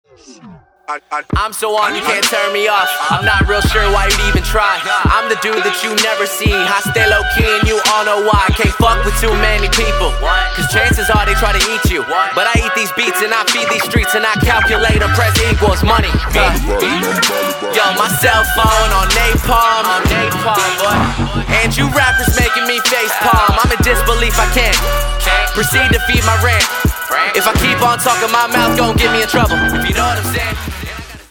• Качество: 192, Stereo
жесткие
качающие
агрессивные
Стиль: rap, trap